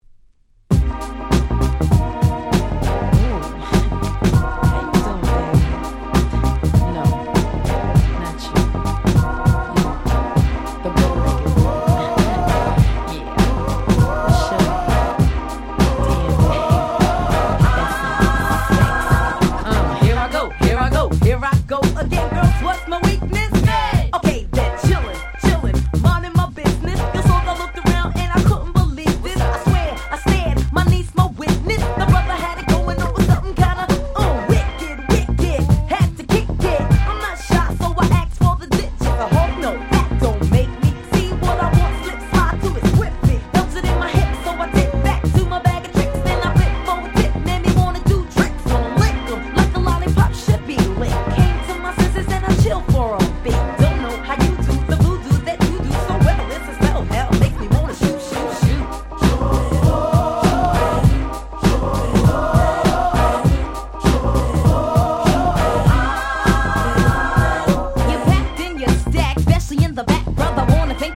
93' Super Hit Hip Hop !!
90's Boom Bap ブーンバップ